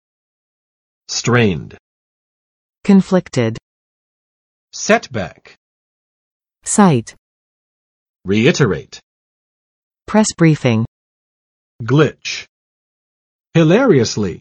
[ˋstrend] adj. 紧张的；勉强的
strained.mp3